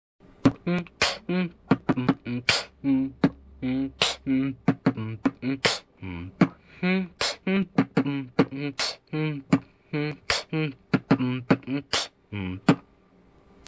"битбоксерский вариант"
b-t-kch-t-bb-t-kch-pf/t-kch-t-bb-bm-- smile